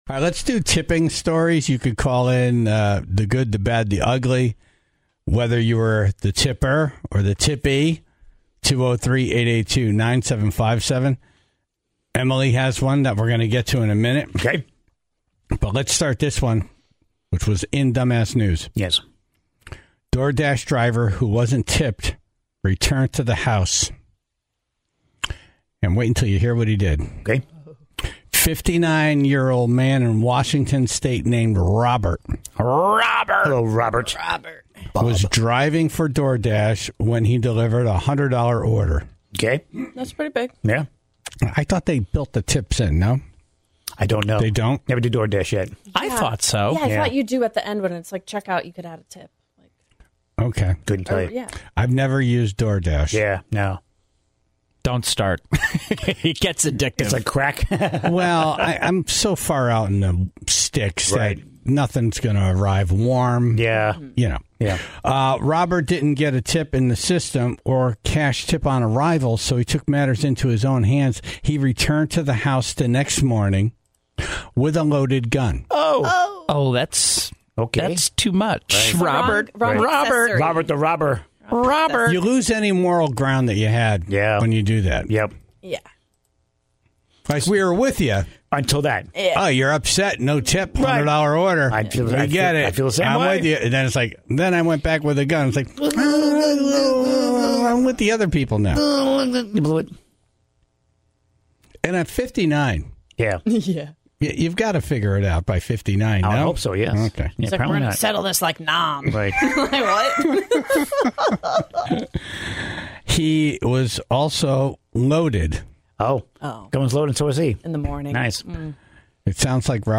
The Tribe was quick to jump in to share their experiences from both sides of the transaction.